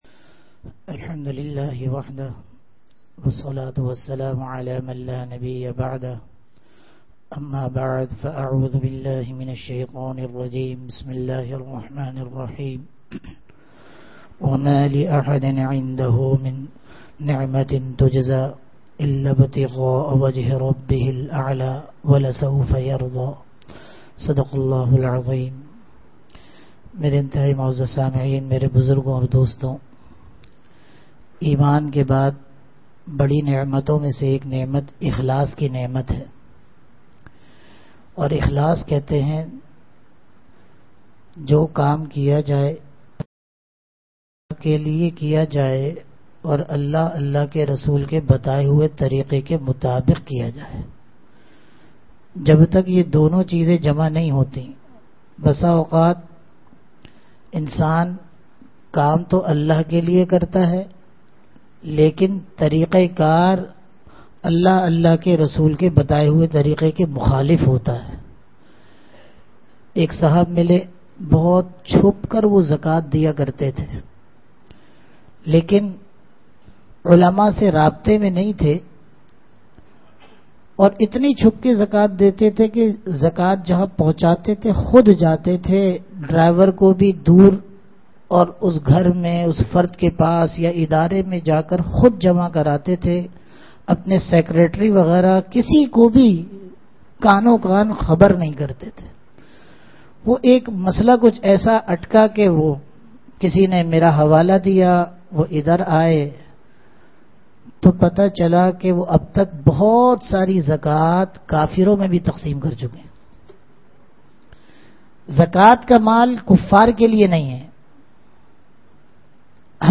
Bayanat
Bayanat (Jumma Aur Itwar) Ikhlas ki dil nasheen tashreeh (jummah byan)